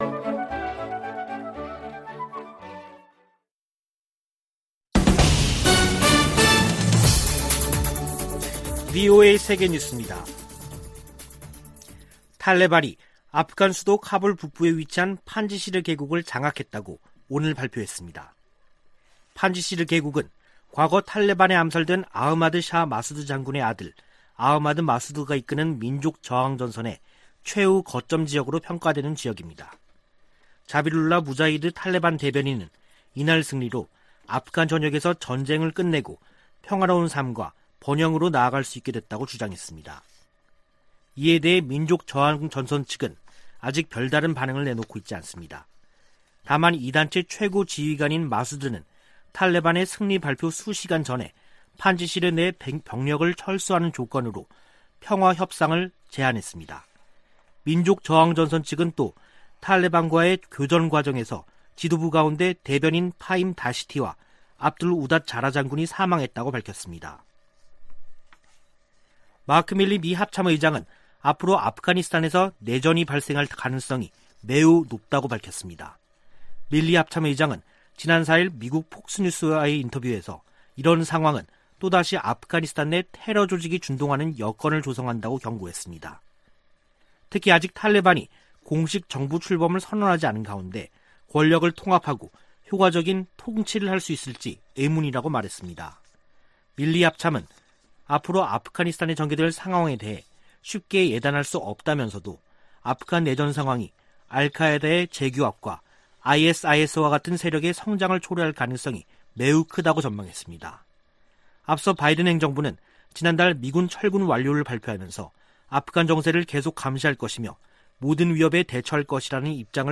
VOA 한국어 간판 뉴스 프로그램 '뉴스 투데이', 2021년 9월 6일 3부 방송입니다. 미 공화당 의원들은 북한 문제와 관련해 강력한 압박을 촉구하고 있는 가운데 일부 민주당 의원은 강경책은 해법이 아니라고 밝혔습니다. 북한이 대륙간탄도미사일 발사를 선택하더라도 이에 맞서 임무 수행할 준비가 돼 있다고 미 북부사령관이 밝혔습니다. 북한의 사이버 위협이 진화하고 있지만 미국의 대응은 제한적이라고 워싱턴의 민간단체가 지적했습니다.